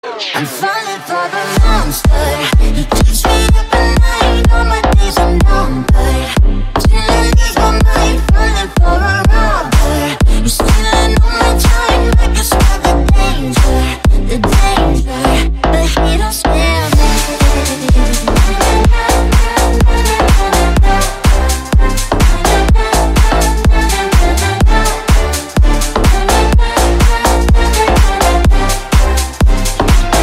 Elektroniczne